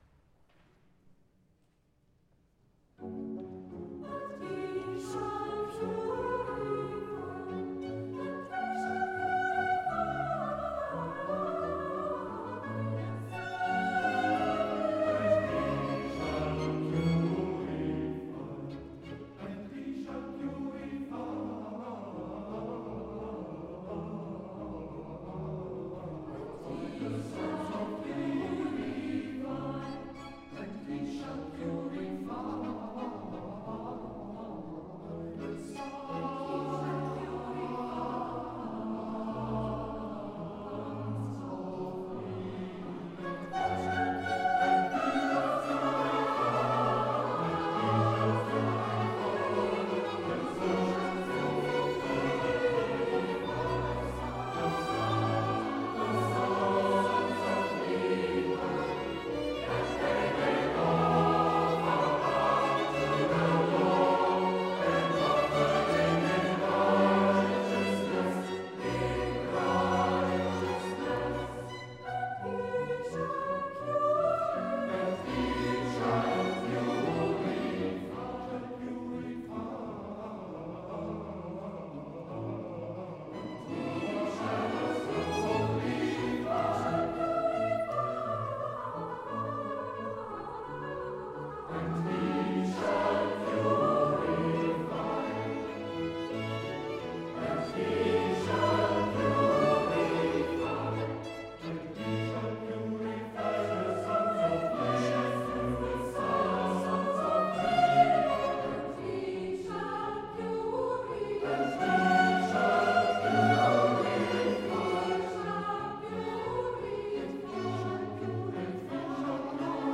Konzerte am 26. April in der Kreuzkirche Ottensen und am 27. April in der Liebfrauenkirche Fischerhude
Live-Mitschnitte: